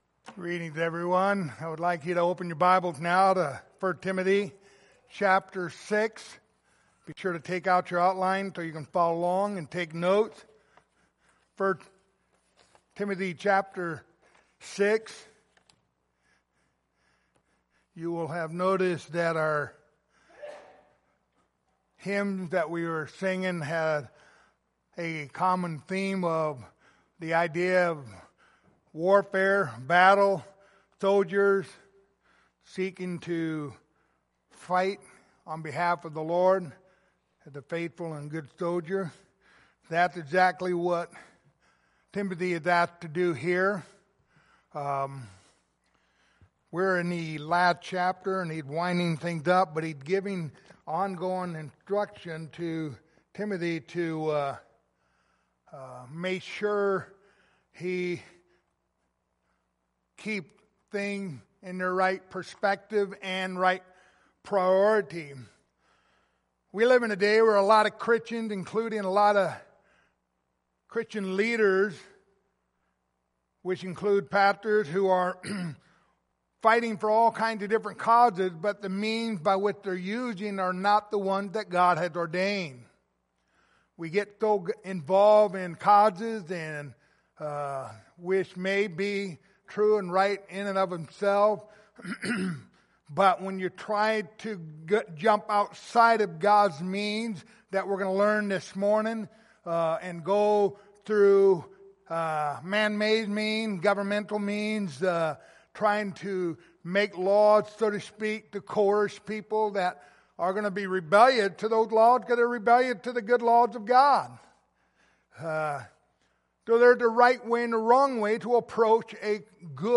Passage: 1 Timothy 6:11-12 Service Type: Sunday Morning